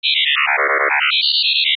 robot sounds